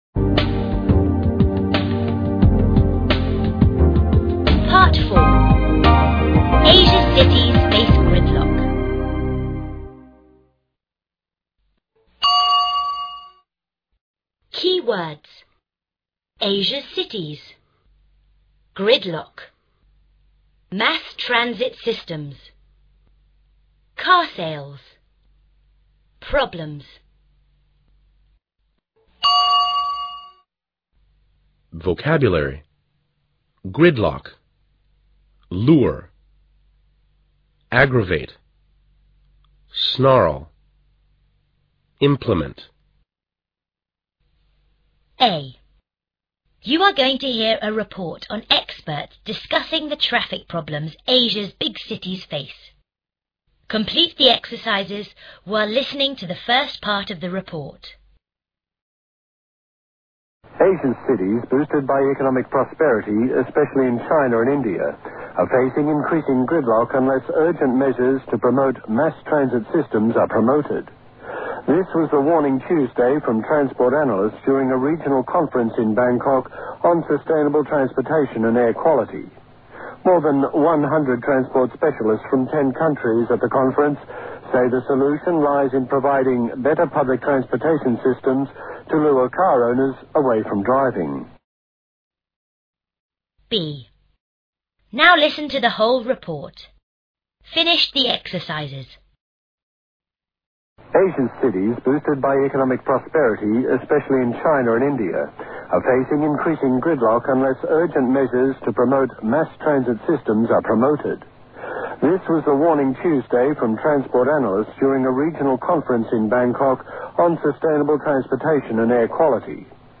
A. You're going to hear a report on experts discussing the traffic problems Asian's big cities face.